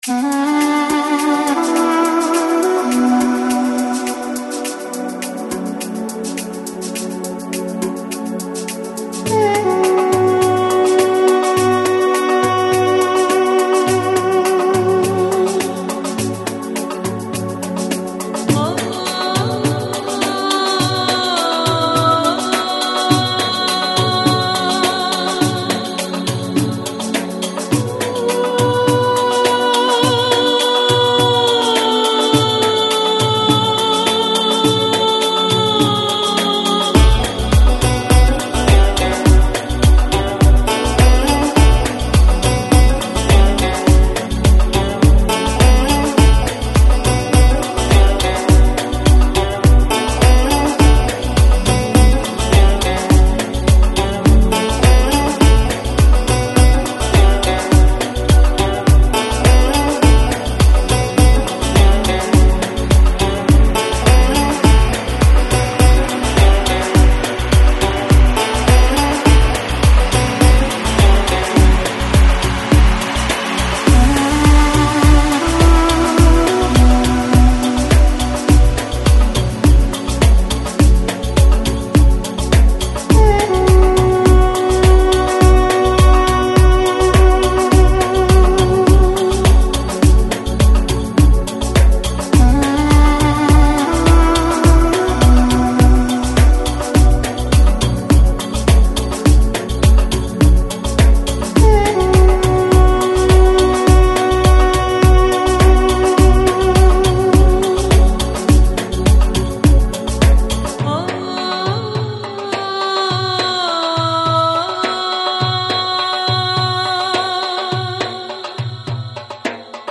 Downtempo, Lounge, Chill Out